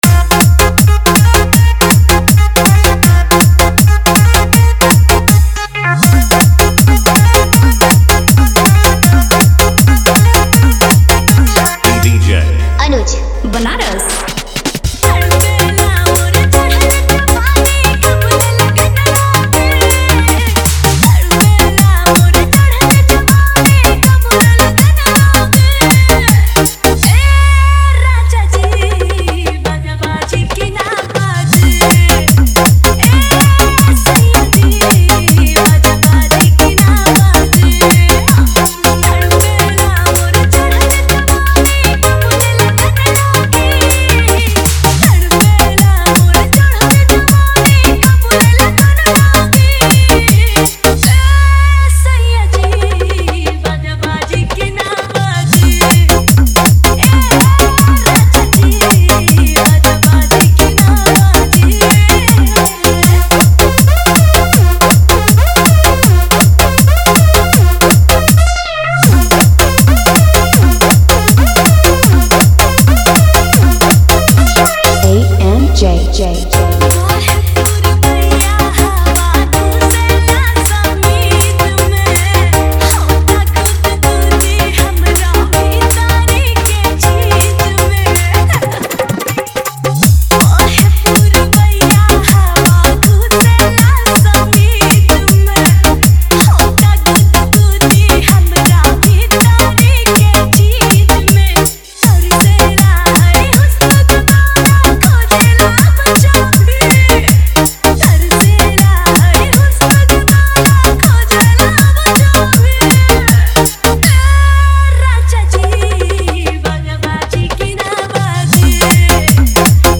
New Bhojpuri Dj Remix